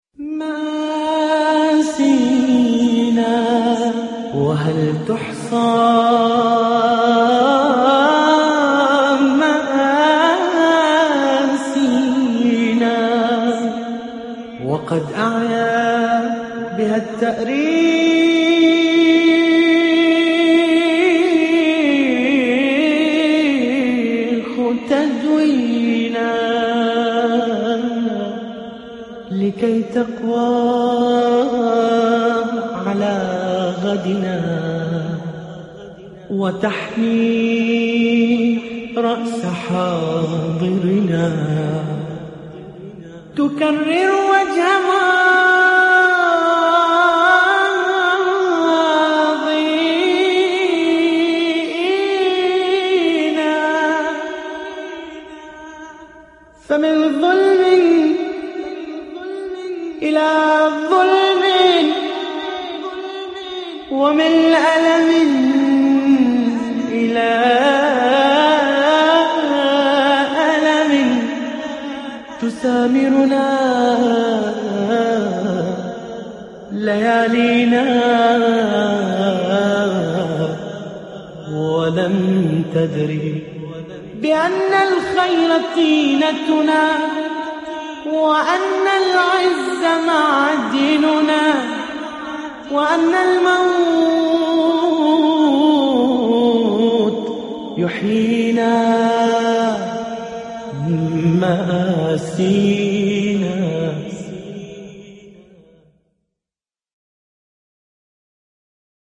نعي , استديو